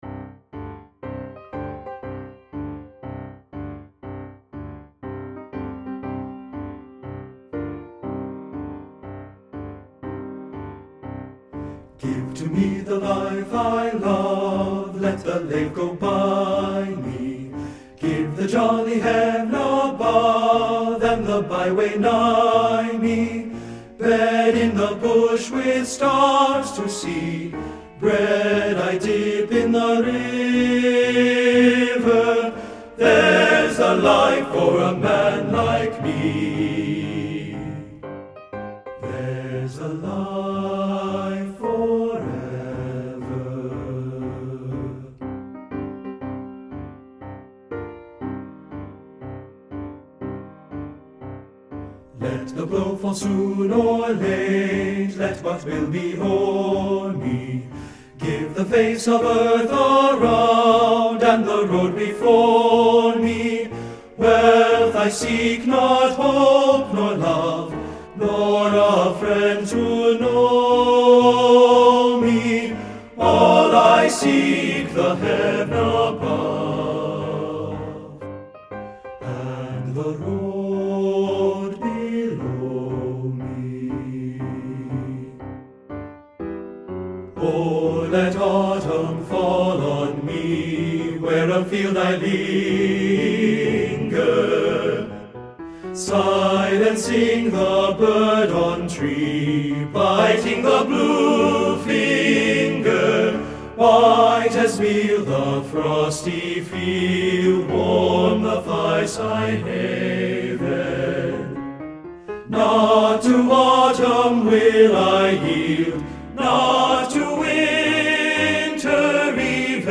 Composer: American Folk Song
Voicing: TTB